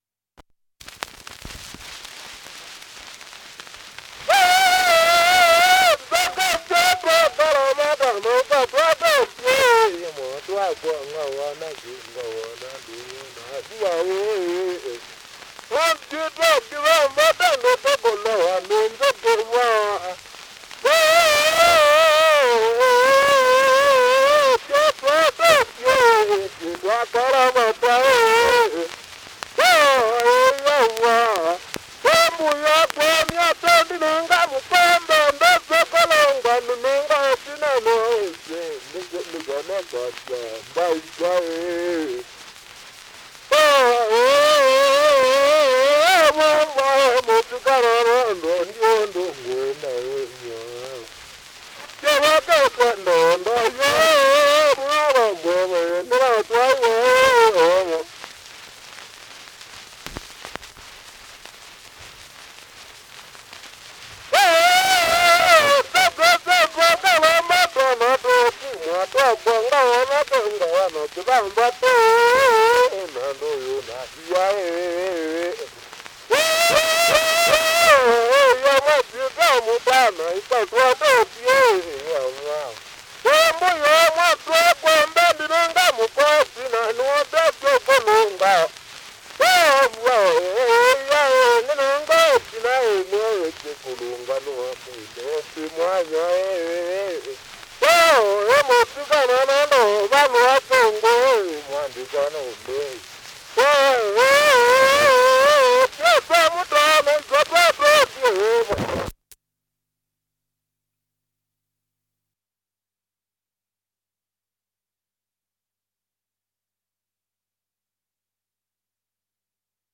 Sång af en man som hänger uti bistockar. Wathi wa Kwunika mwotu.
Ljudinspelningar från Brittiska östafrika (Kenya) 1911